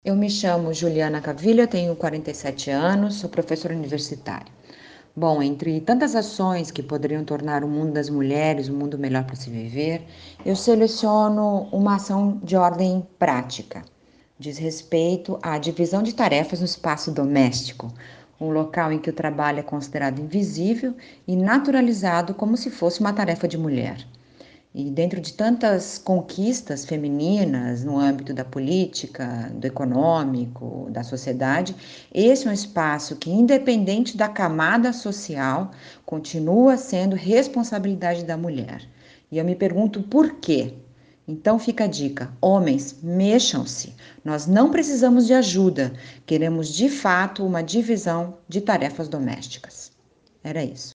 Ouvimos mulheres de várias idades, que viveram momentos diferentes dessa história, para saber o que ainda falta para o mundo se tornar um lugar bom para elas.